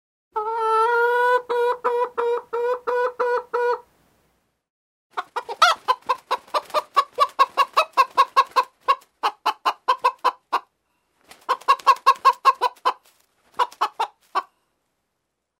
Звуки курицы
1. Курочка кудахчет n2. Кудахтанье курицы